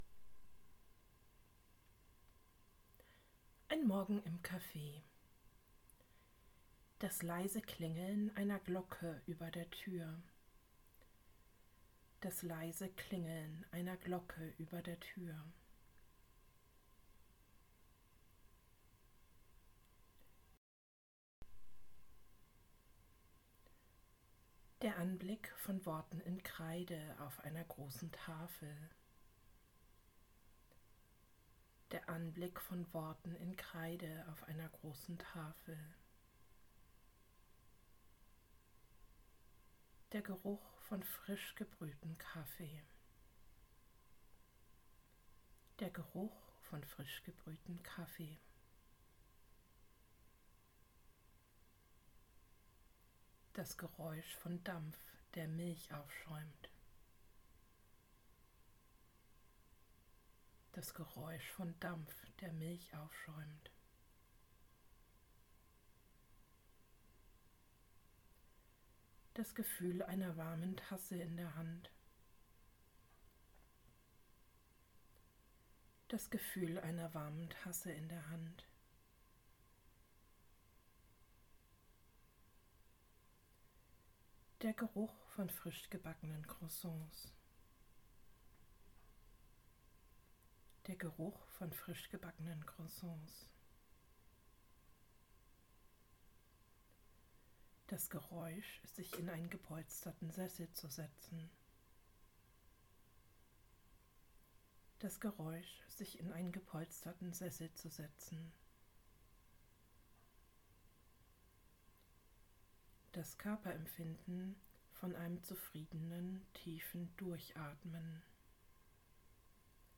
In dieser Kurzversion bekommt ihr Anleitung, euch eine Sinneserfahrung nach der anderen vorzustellen. Die Anleitung wird zweimal wiederholt und dann werdet ihr direkt zum nächsten Bild oder Sinn übergeleitet.